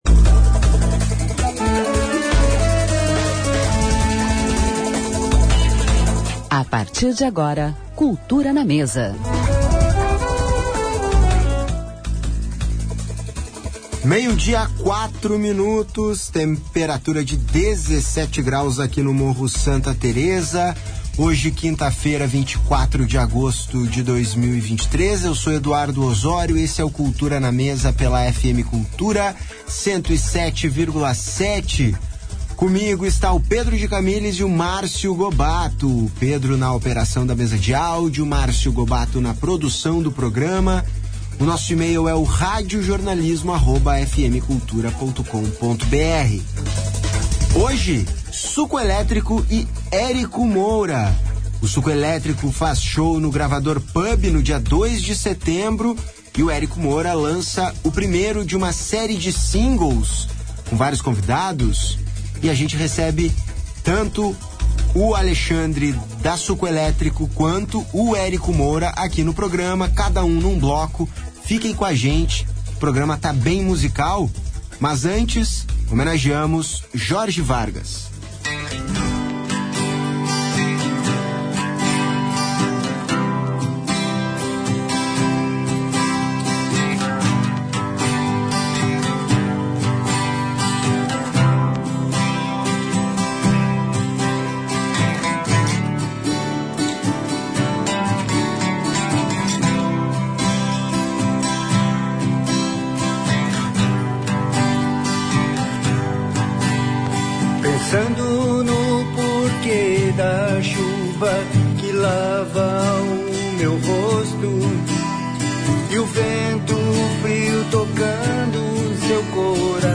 Entrevista e música ao vivo